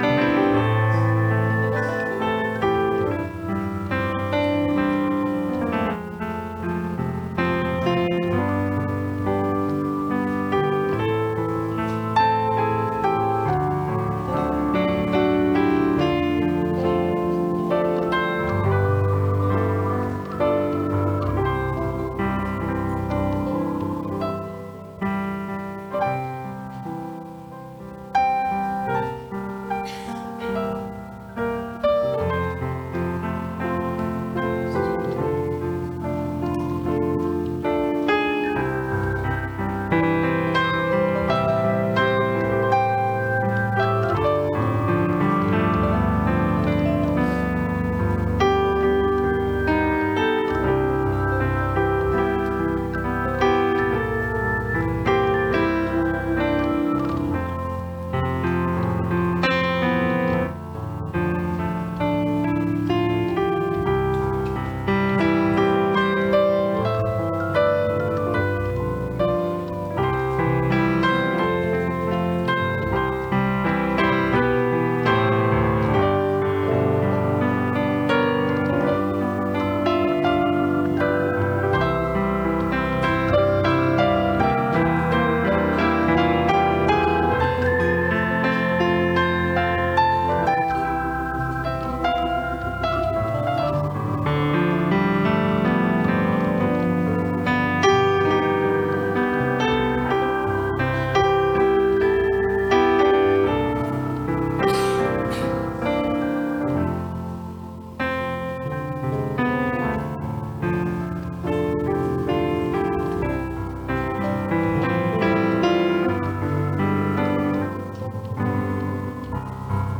Passage: Acts 15:18 Service Type: Sunday Morning